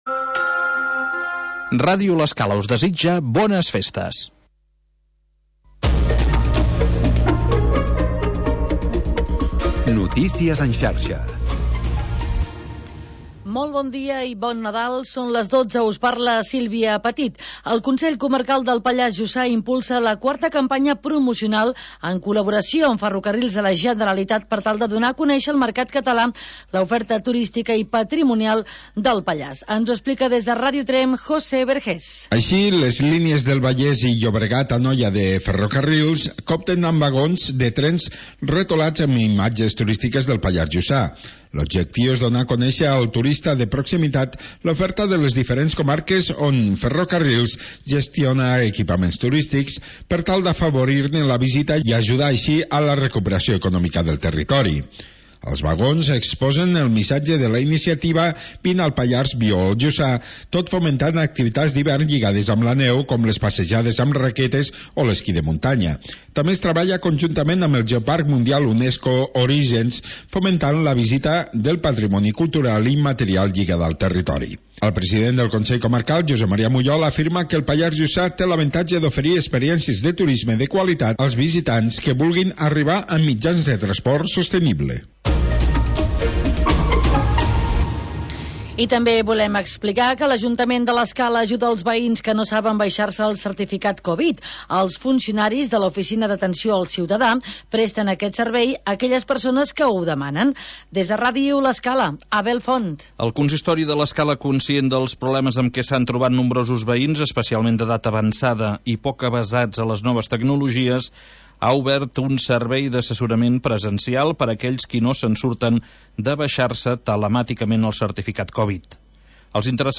Programa sardanista